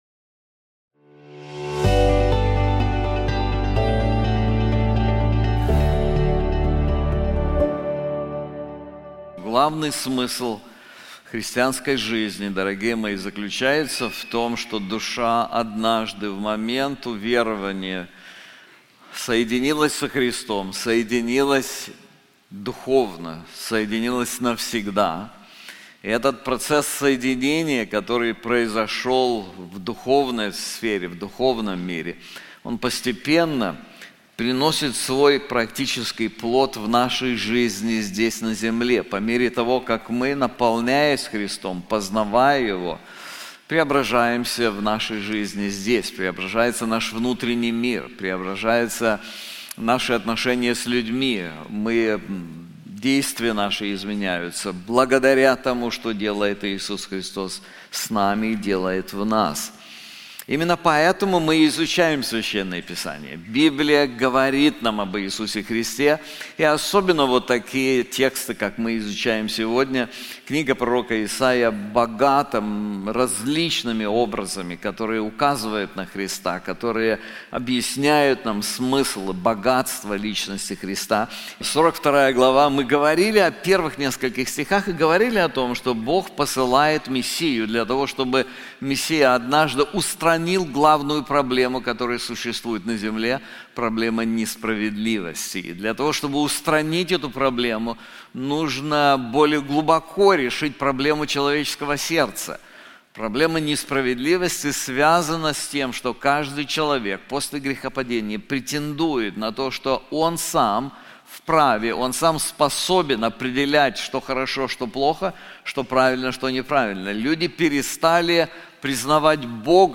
This sermon is also available in English:The Power of Christ's Ministry • Part 1 • Isaiah 42:1-28